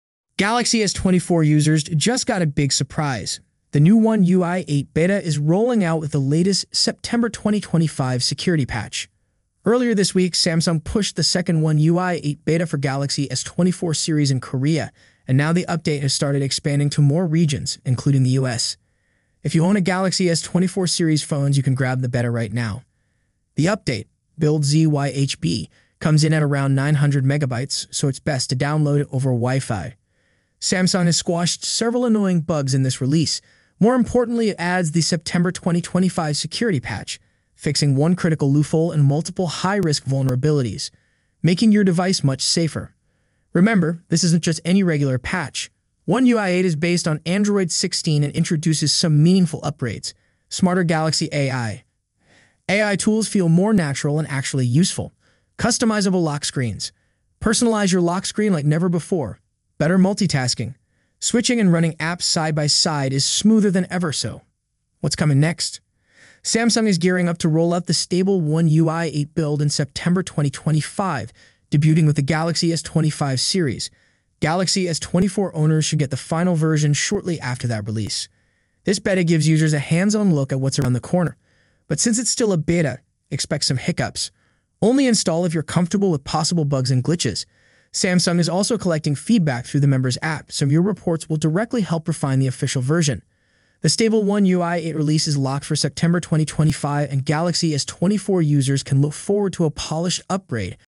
Samsung One UiI 8 NEW Sound Effects Free Download